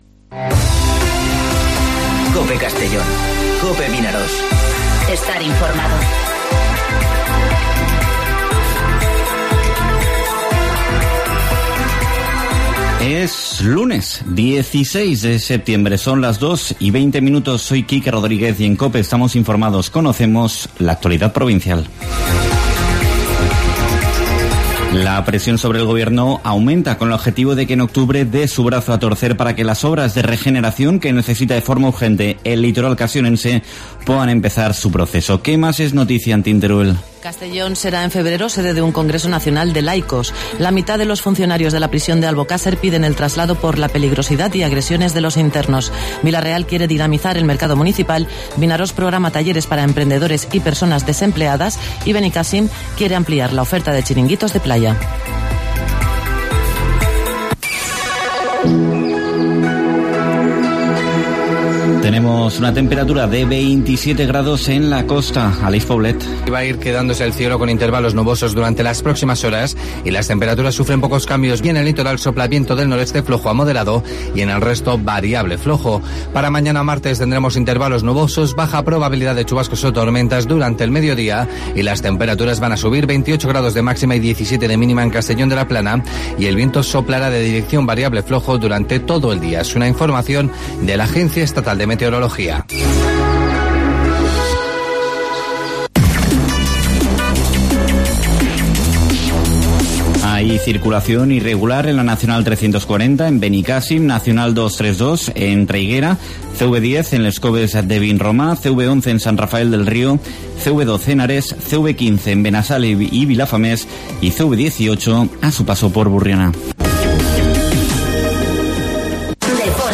Informativo Mediodía COPE en Castellón (16/09/2019)